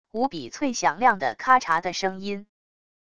无比脆响亮的喀察的声音wav音频